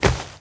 SFX_saltoSacos2.wav